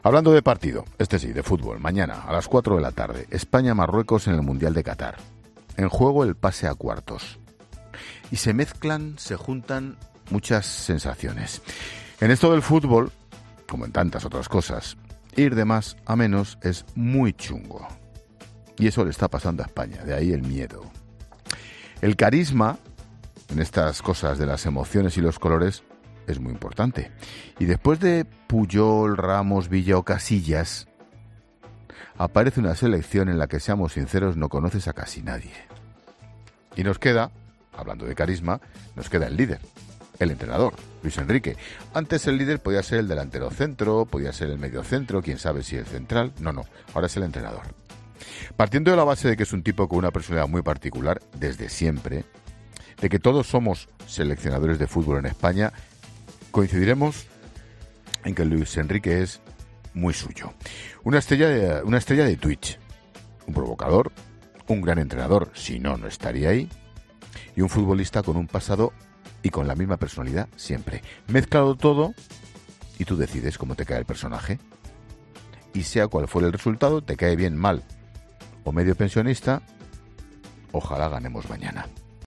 El director de La Linterna, Ángel Expósito, ha querido dedicar parte del espacio de su monólogo de este lunes al seleccionador español, Luis Enrique, tan sólo a unas horas del encuentro que medirá a España con Marruecos y en la que nos jugamos el pase a cuartos de final.